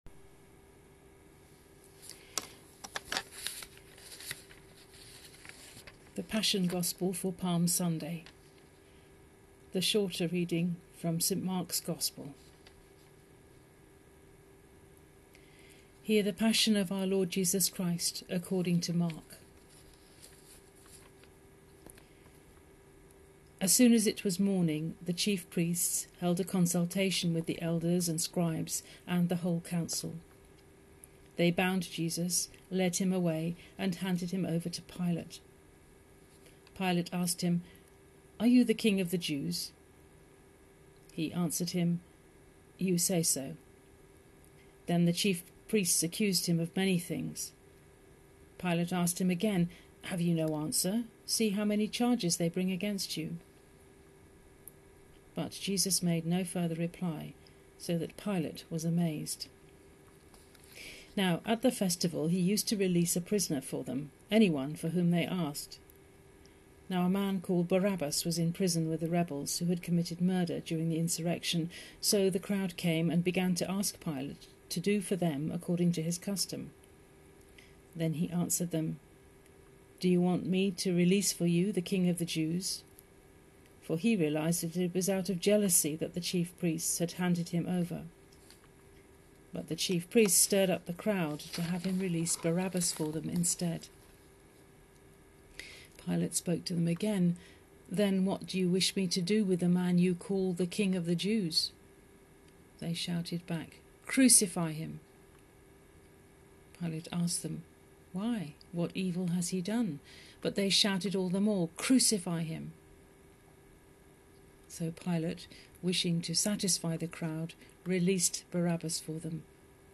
Passion Reading
Passion-Gospel-for-Palm-Sunday.mp3